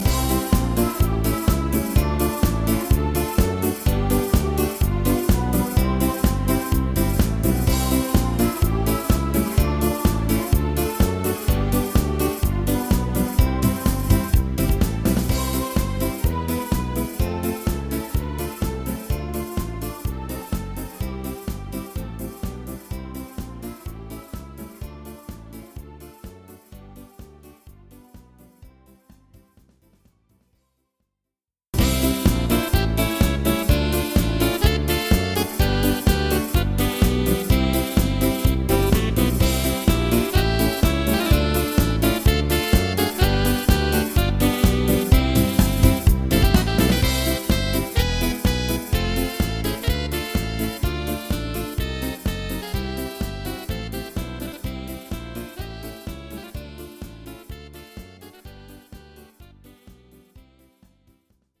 Rubrika: Národní, lidové, dechovka
Slovenská ľudová
Verbuňk
vyrobený podle předlohy cimbálové kapely